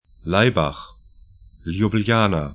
Pronunciation
Laibach 'laibax Ljubljana ljʊbl'ja:na sl Stadt / town 46°03'N, 14°31'E